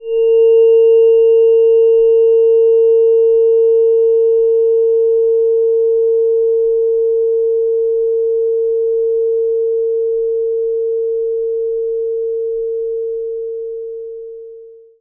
fork_High-Full-Moon.mp3